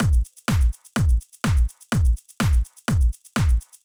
Drumloop 125bpm 03-C.wav